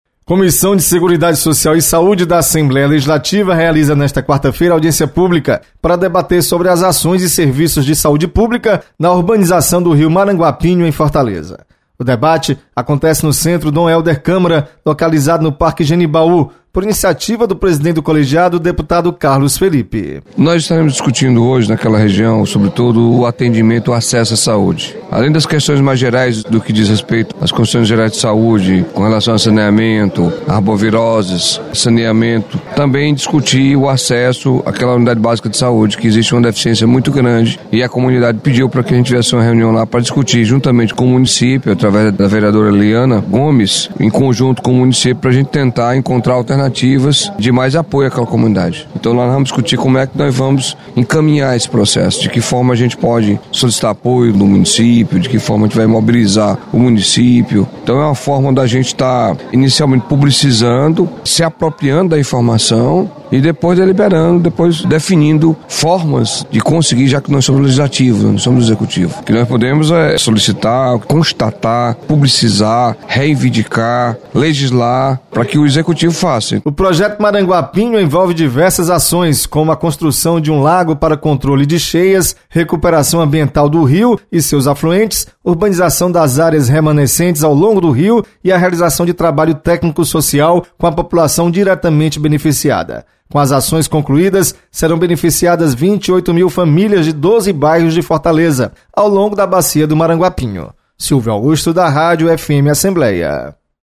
Comissão de Seguridade Social vai debater ações de saúde nas comunidades do Rio Maranguapinho. Repórter